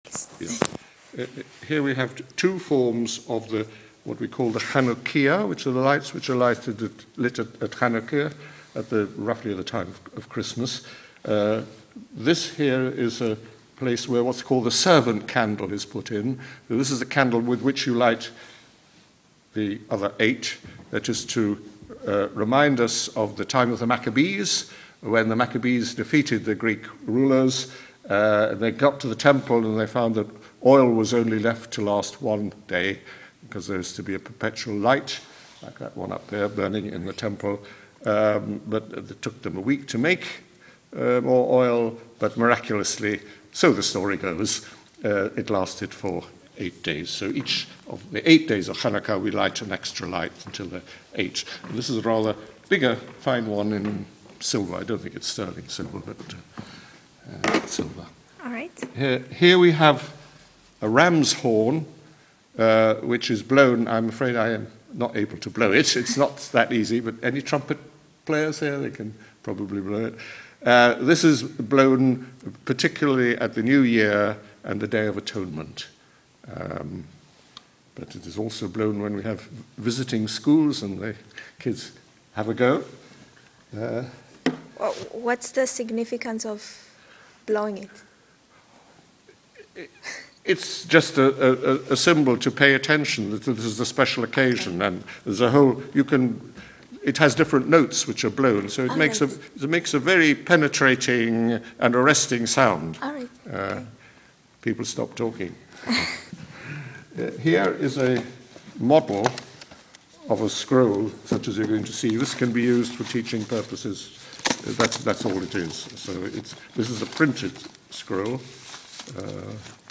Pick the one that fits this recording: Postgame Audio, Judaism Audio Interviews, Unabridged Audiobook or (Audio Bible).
Judaism Audio Interviews